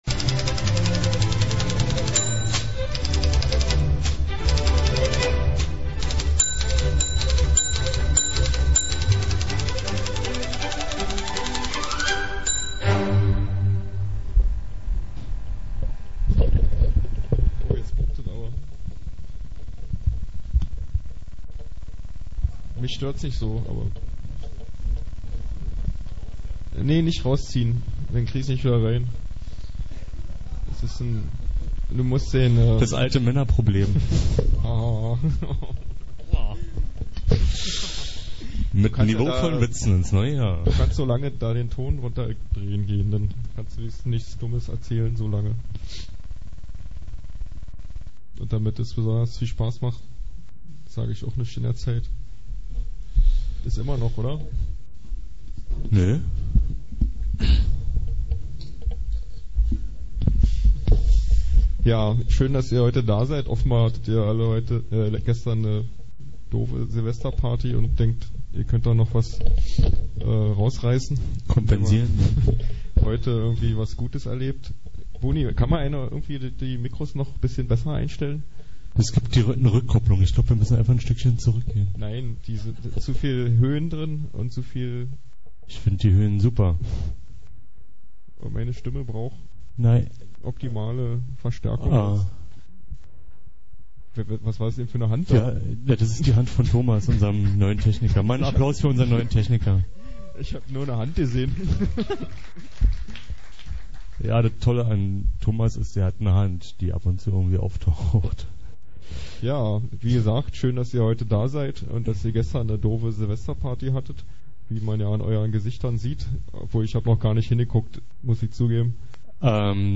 Ansage vom 1.1.2004